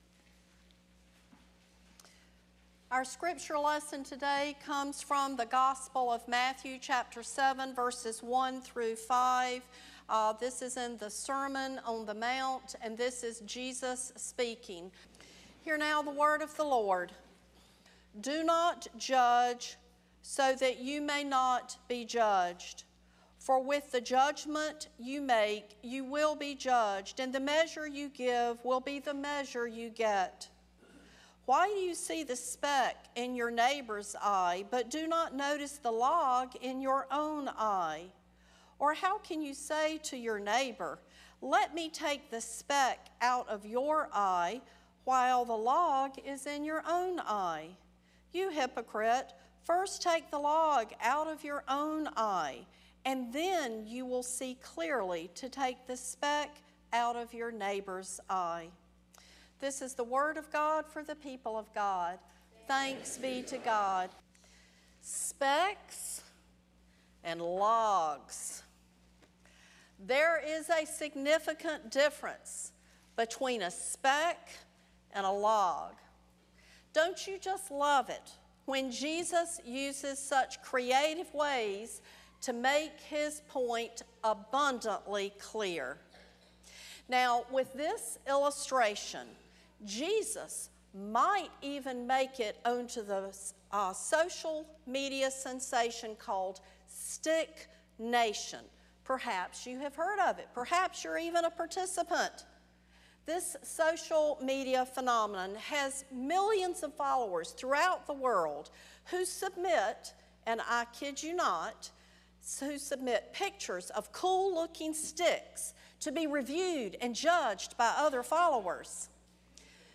“humbled” Sermon Series, Week 2